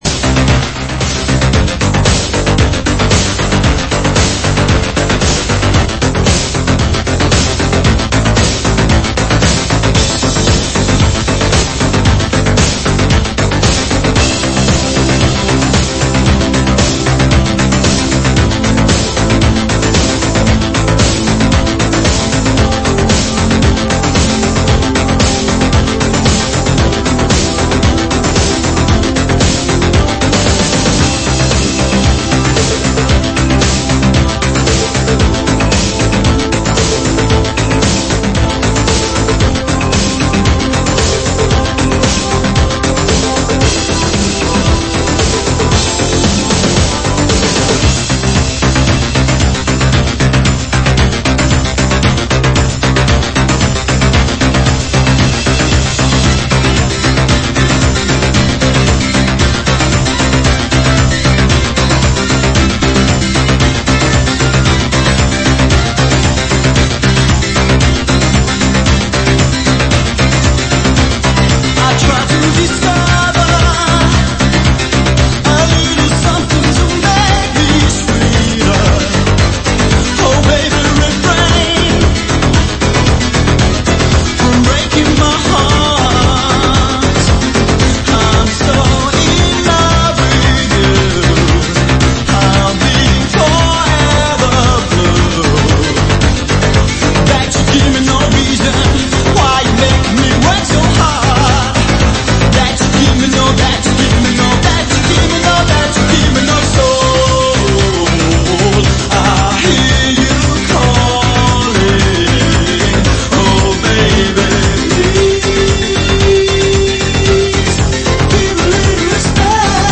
Un poco de música para pasar el rato...espero te guste